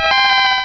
Cri de Lainergie dans Pokémon Rubis et Saphir.